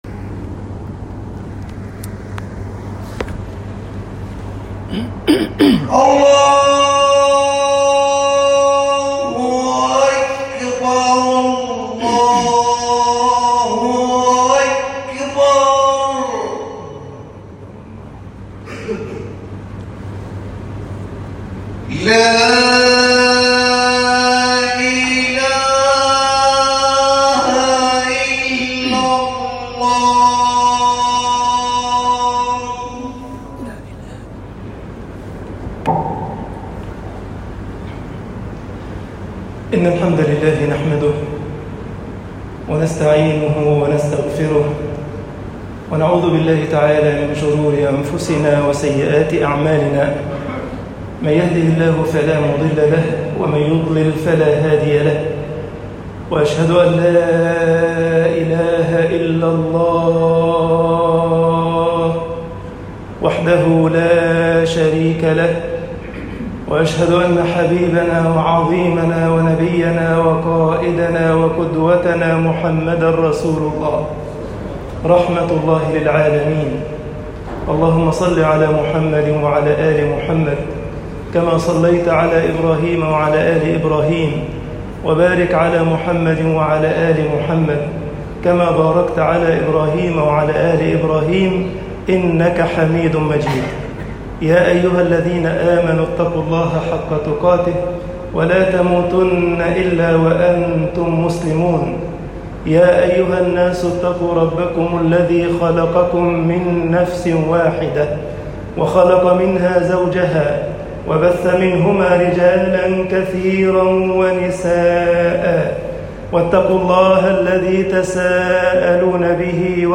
خطب الجمعة - مصر الإسْلامُ وَمَصَالِحُ الْأنَامِ طباعة البريد الإلكتروني التفاصيل كتب بواسطة